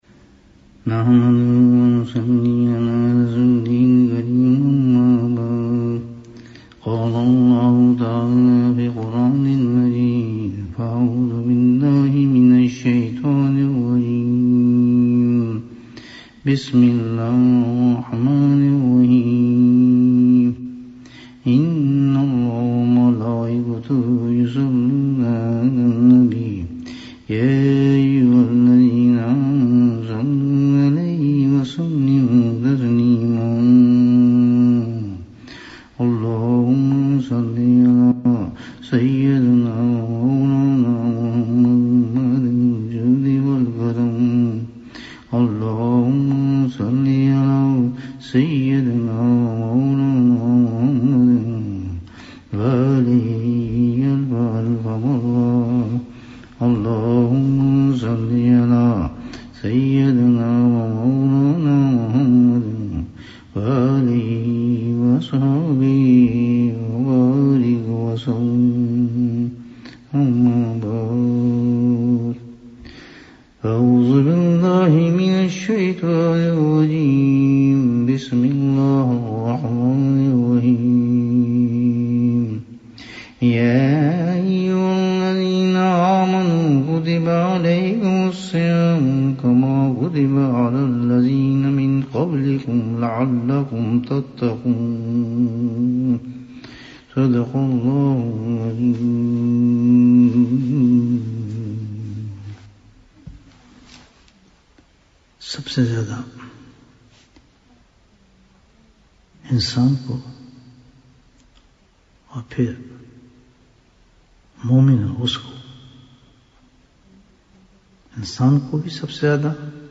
غفلت کا علاج کیا ہے؟ Bayan, 33 minutes10th May, 2020